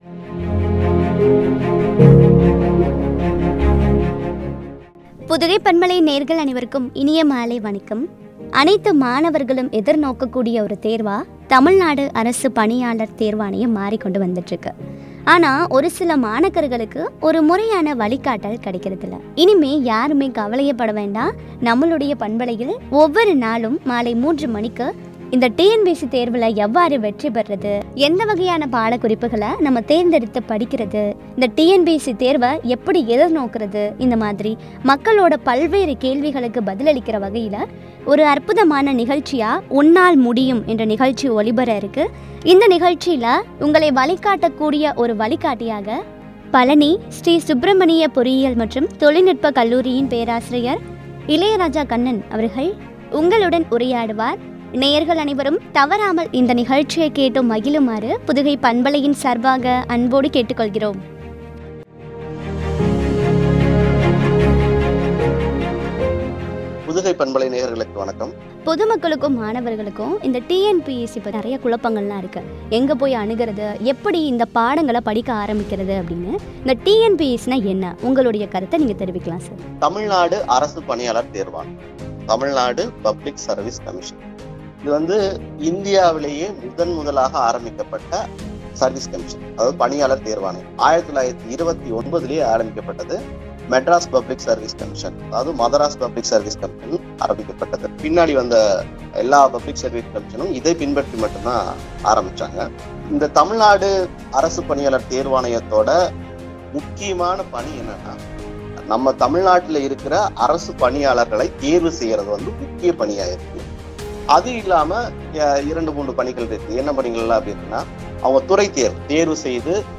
“உன்னால் முடியும்” என்ற தலைப்பில் வழங்கிய உரையாடல்.